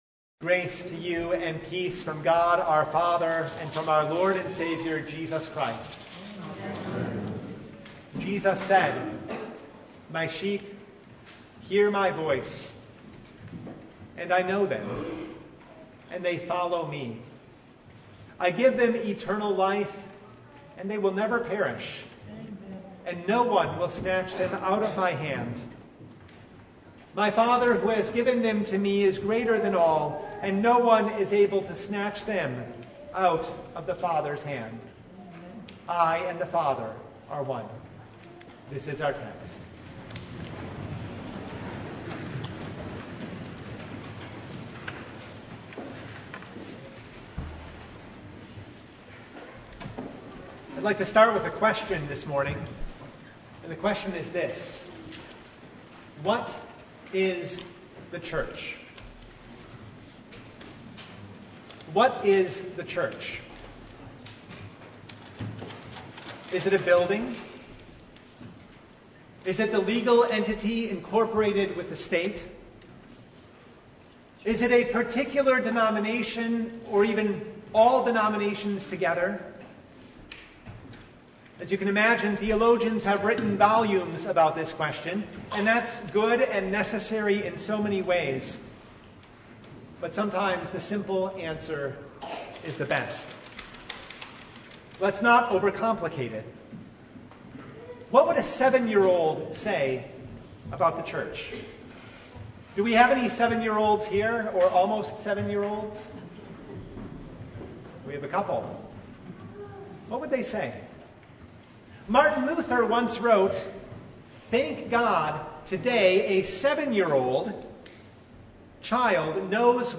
Service Type: Good Shepherd Sunday